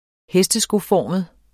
Udtale [ ˈhεsdəsgoˌfɒˀməð ]